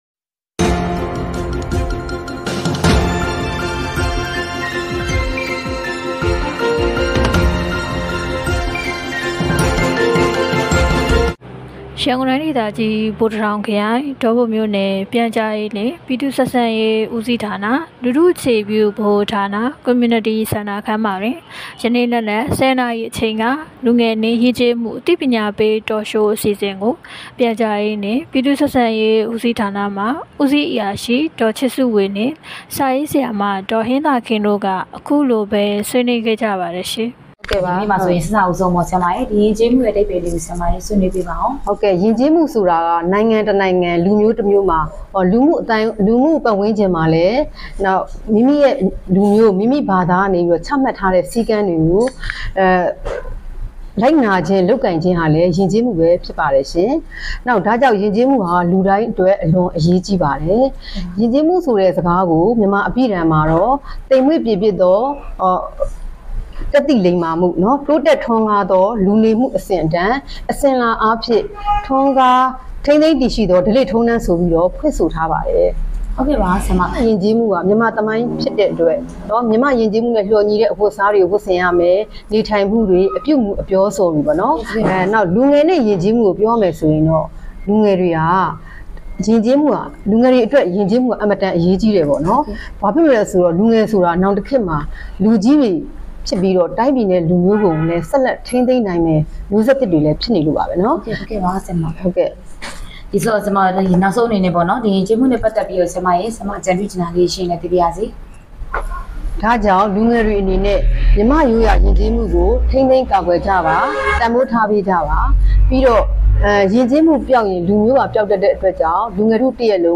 ဒေါပုံမြို့နယ်တွင် လူငယ်နှင့်ယဉ်ကျေးမှုအသိပညာပေး Talk Show